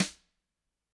Index of /90_sSampleCDs/ILIO - Double Platinum Drums 1/CD4/Partition C/GRETCHBRSNRD